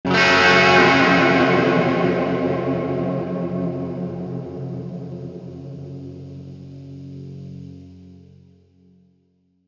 Spaghetti Western Guitar Stab
guitar chord with some EQ, delay, and reverb. sounds neat
westernguitarstab_0.mp3